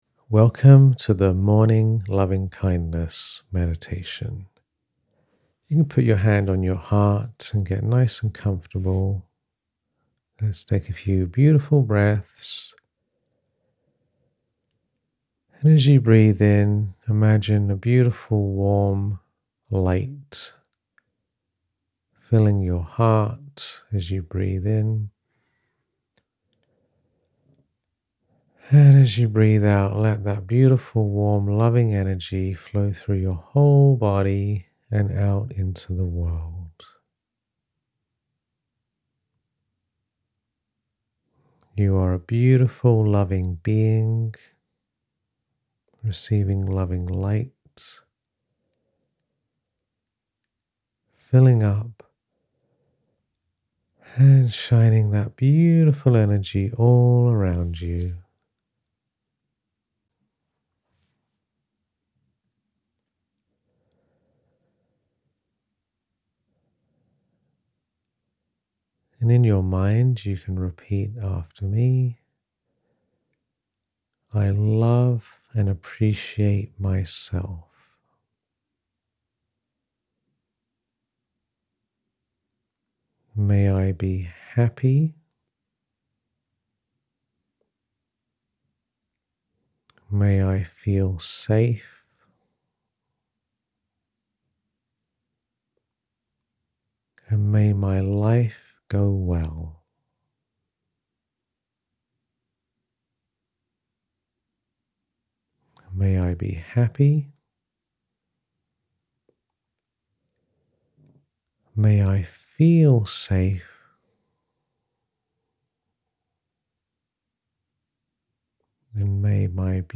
[Meditation] Loving Kindness Meditation